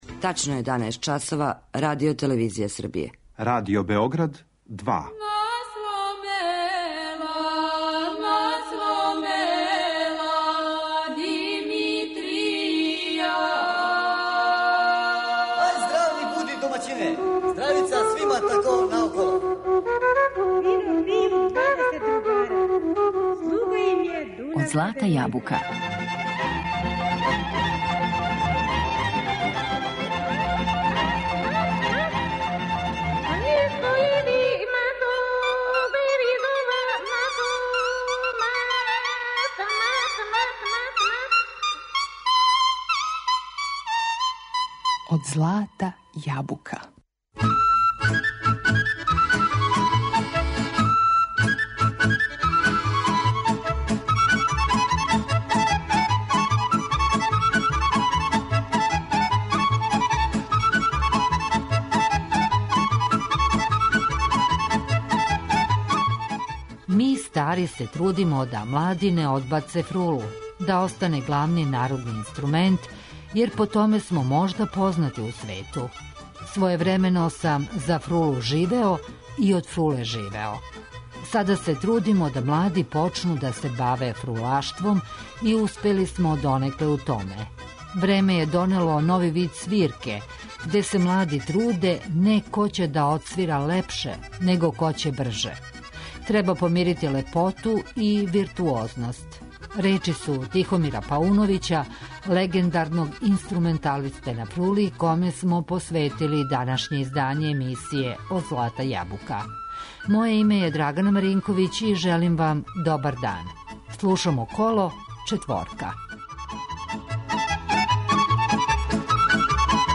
фрули
Његов начин свирања ризница је музичке баштине и непролазних вредности свега онога што је најбоље у влашкој и српској инструменталној музици.